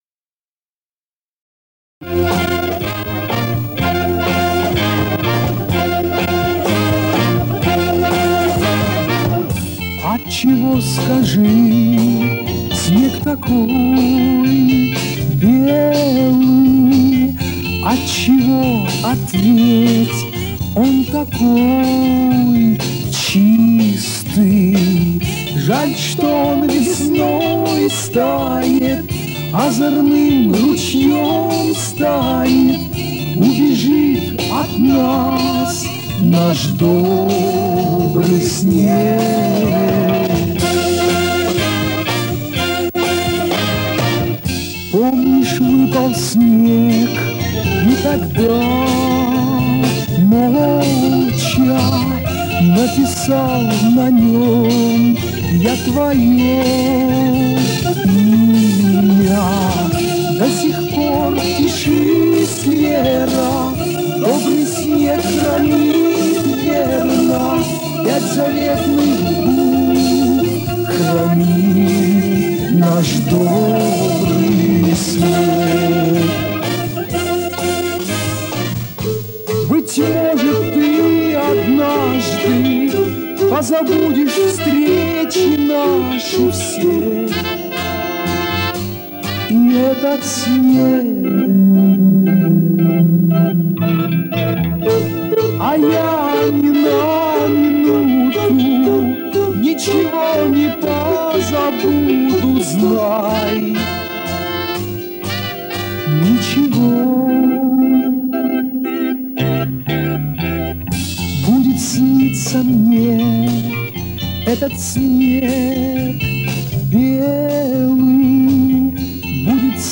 Битрейт 160, но звучит хорошо.